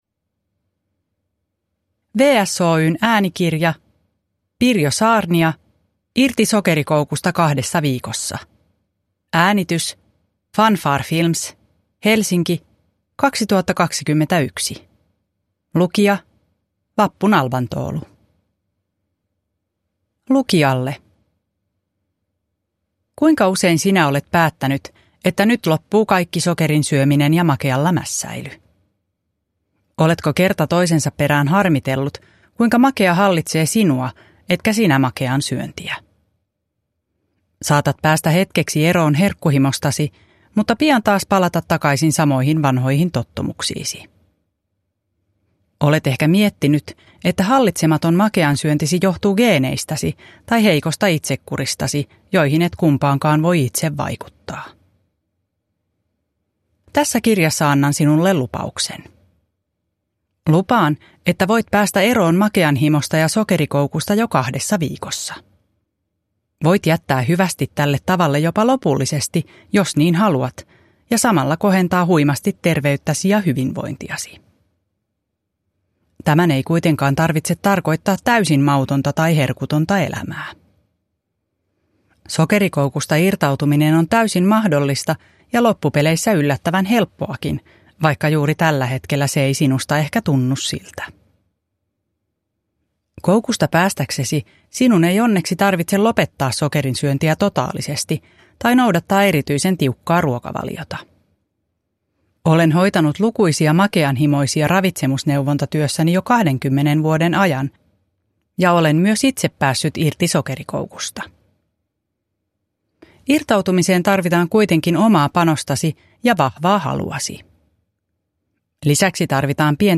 Irti sokerikoukusta 2 viikossa – Ljudbok – Laddas ner